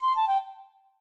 flute_c1ag.ogg